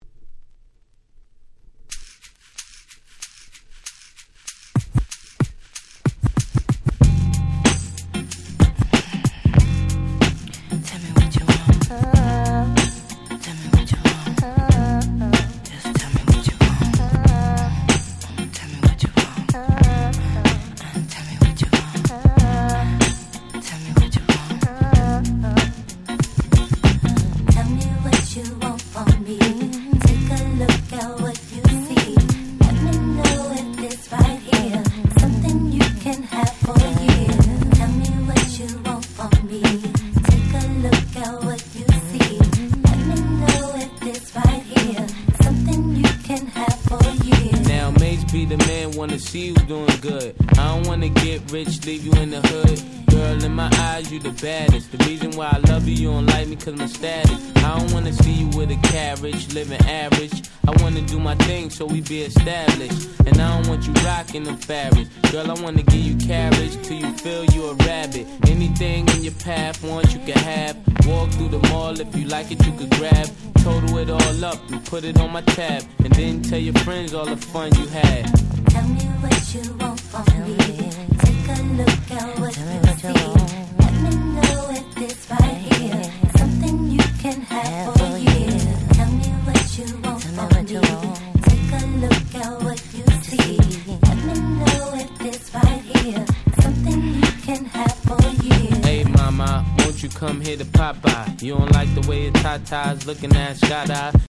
98' Super Hit Hip Hop !!
Boom Bap ブーンバップ